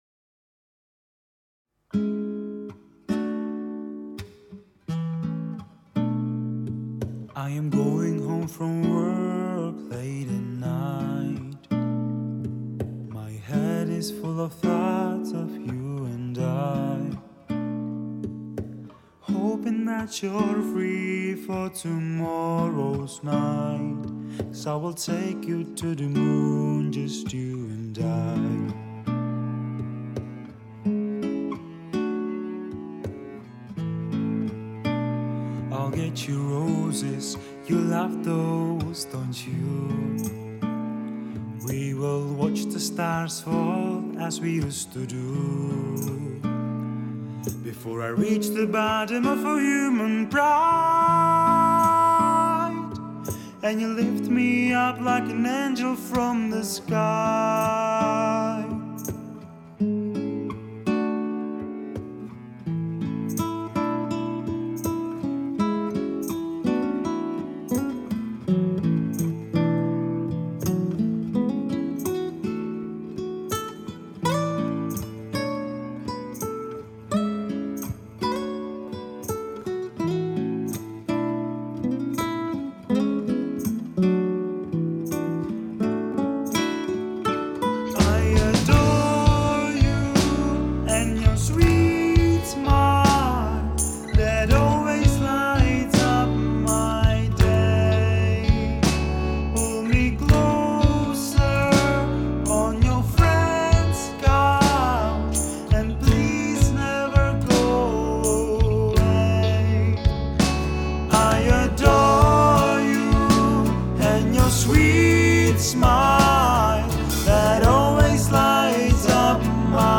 Lead Vocal
Backup Vocals